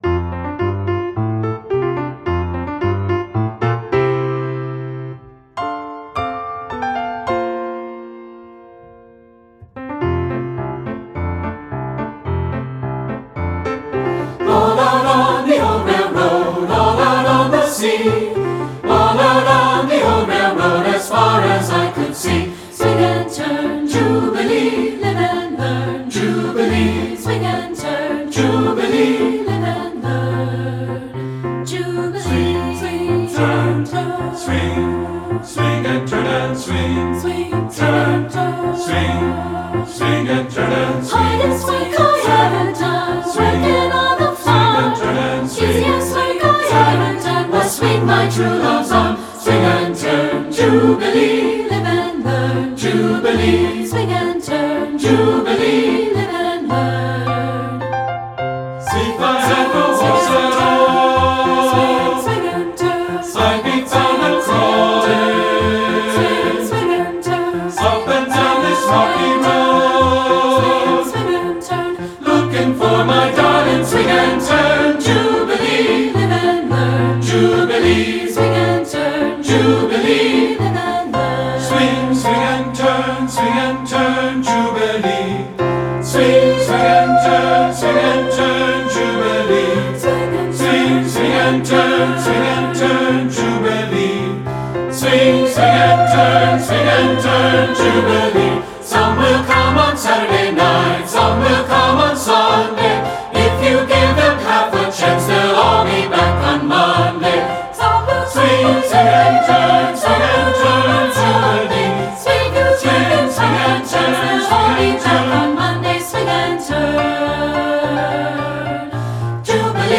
Voicing SATB